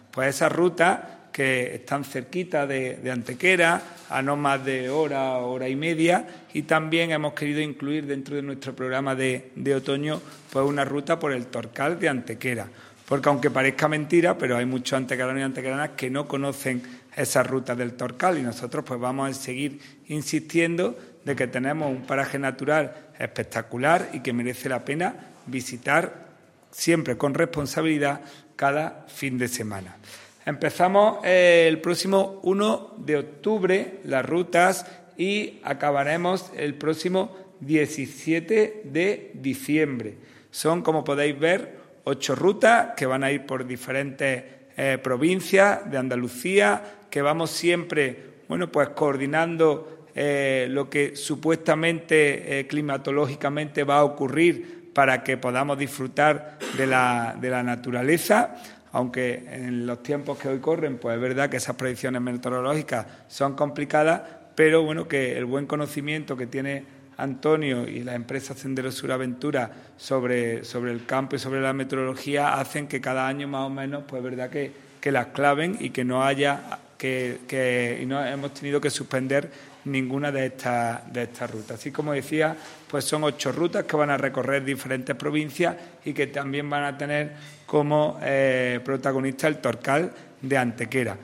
El teniente de alcalde delegado de Deportes, Juan Rosas, ha presentado hoy en rueda de prensa el nuevo programa de Senderismo que se llevará a cabo durante el otoño impulsado por el Área de Deportes del Ayuntamiento de Antequera.
Cortes de voz